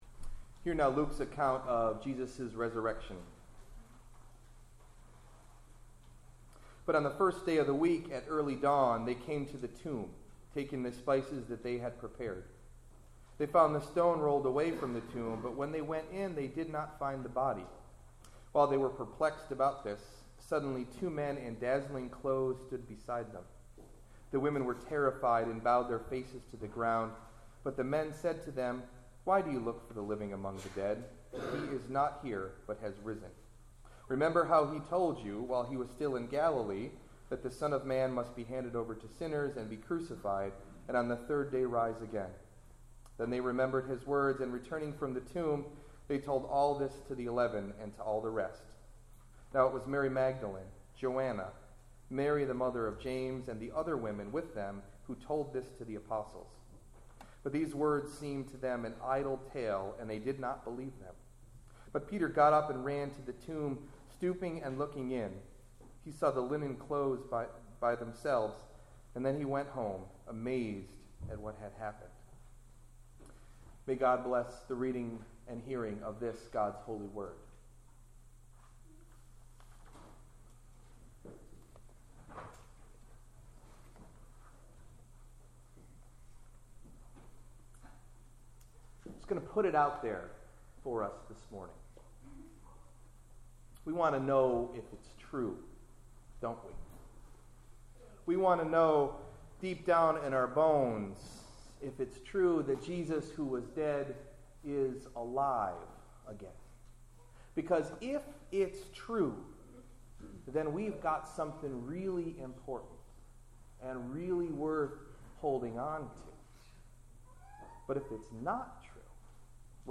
Sermon: “Good Enough”
Delivered at: The United Church of Underhill